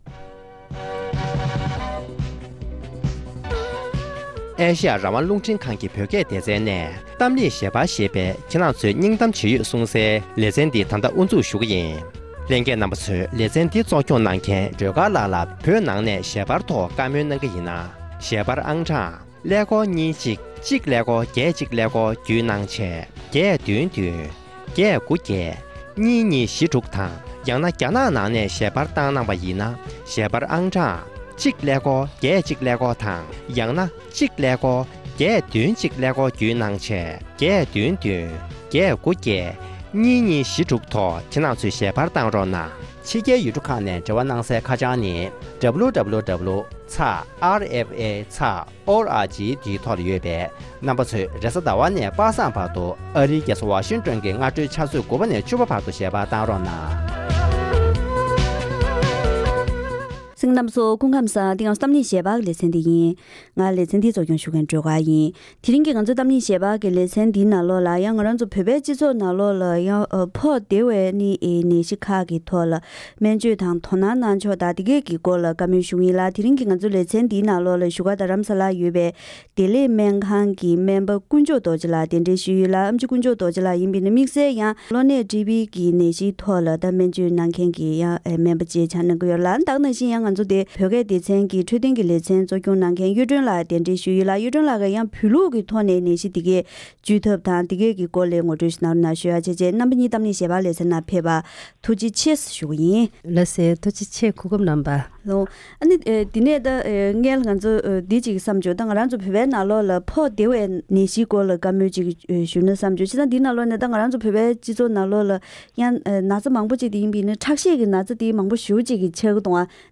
བོད་ཕྱི་ནང་གཉིས་ཀྱི་བོད་མིའི་ཁྲོད་ཕོག་བདེ་བའི་ནད་གཞིའི་ཐོག་བོད་ལུགས་དང་ཕྱི་ལུགས་སྨན་པའི་ལྷན་ནད་གཞིར་ཤེས་རྟོགས་དང་སྔོན་འགོག་སོགས་འབྲེལ་བའི་སྐོར་གླེང་མོལ་ཞུས་པའི་དམིགས་བསལ་ལེ་ཚན།